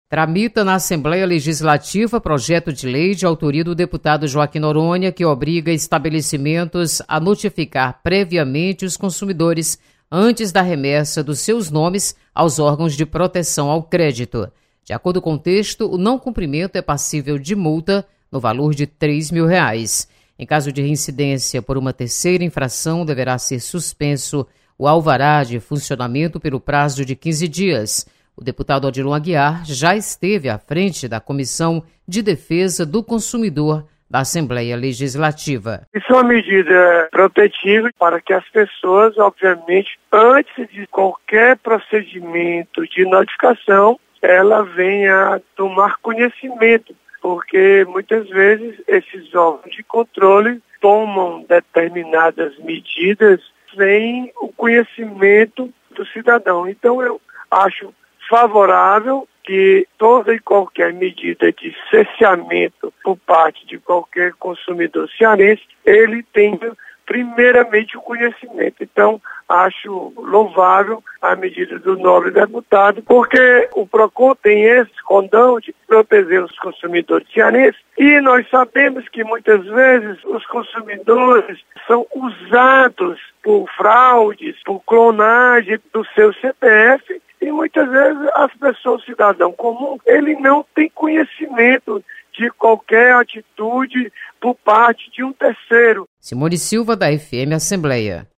Projeto prevê aviso prévio para negativação de nome do consumidor por dívida. Repórter